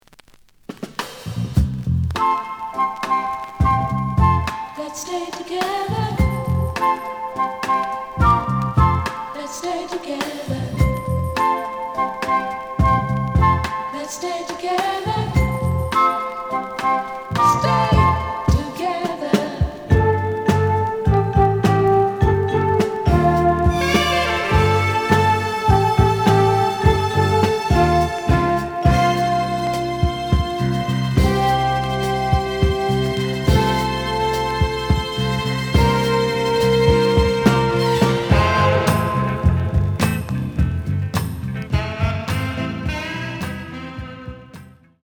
The audio sample is recorded from the actual item.
●Genre: Soul, 70's Soul
Slight affect sound.